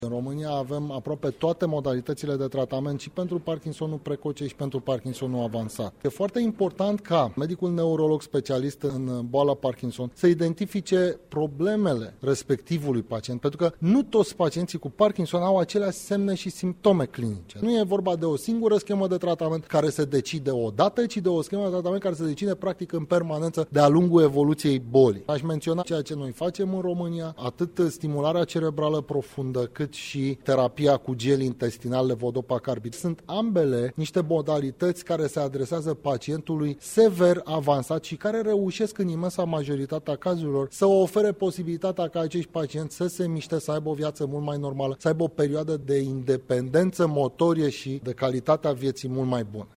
medicul neurolog